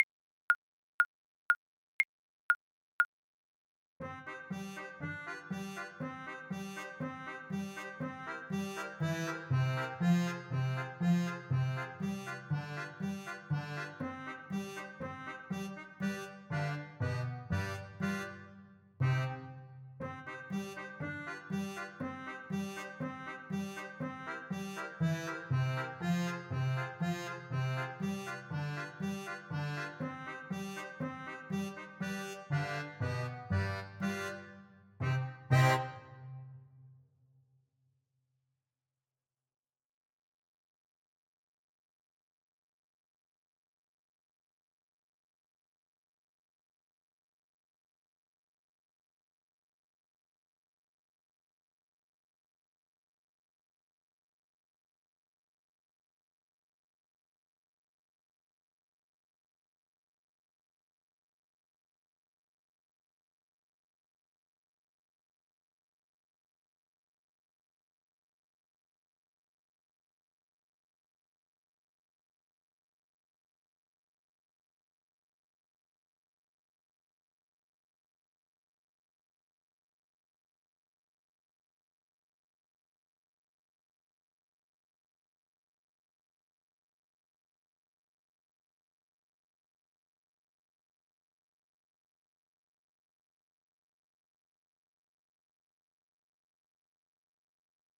Play (or use space bar on your keyboard) Pause Music Playalong - Piano Accompaniment transpose reset tempo print settings full screen
Trumpet
C minor (Sounding Pitch) D minor (Trumpet in Bb) (View more C minor Music for Trumpet )
4/4 (View more 4/4 Music)
Allegro = 120 (View more music marked Allegro)
World (View more World Trumpet Music)
Israeli